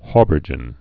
(hôbər-jən)